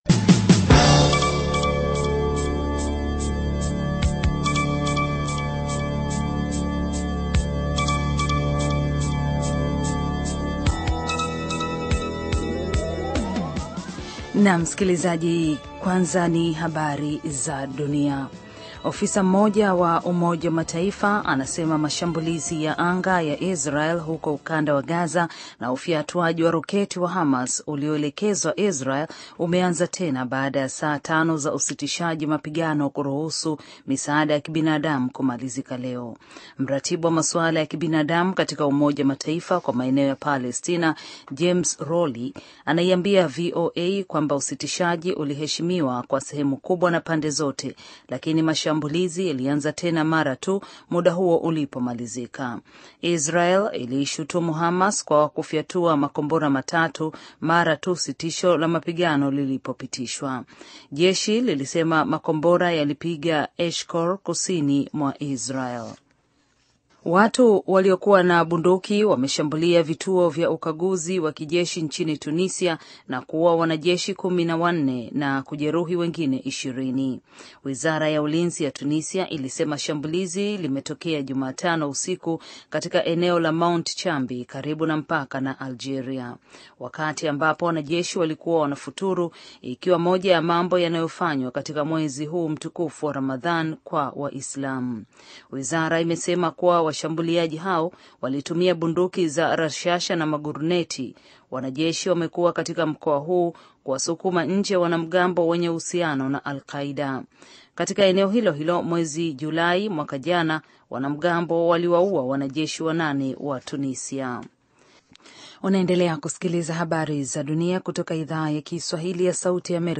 Taarifa ya Habari VOA Swahili - 6:42